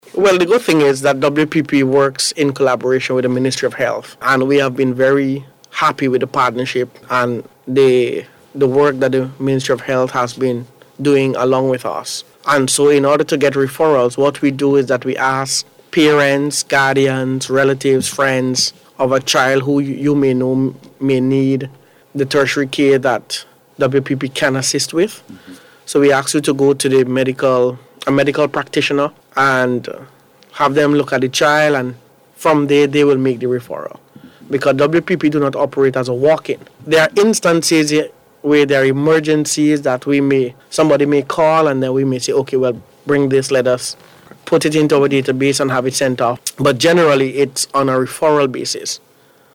provided an overview of the WPP during the Talk Yuh Talk program on NBC Radio this morning